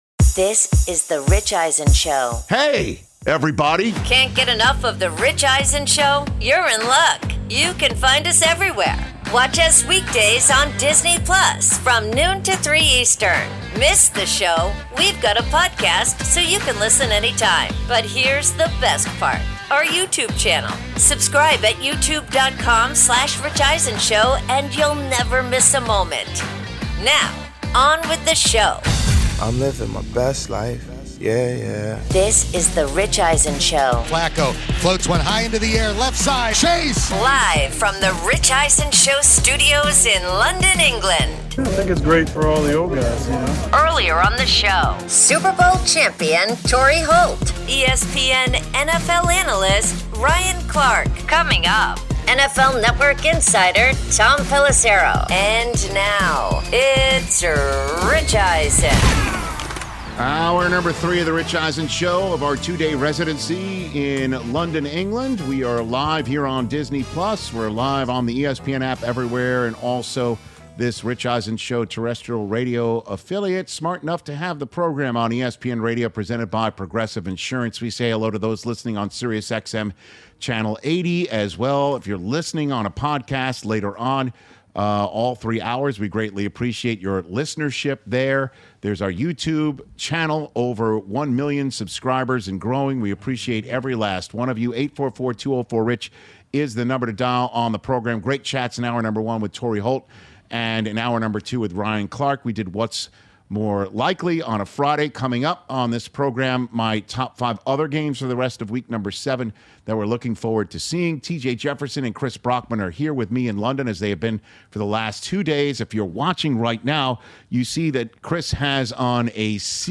Hour 3: Live from London with Week 7’s Top Games, plus NFL Insider Tom Pelissero Podcast with Rich Eisen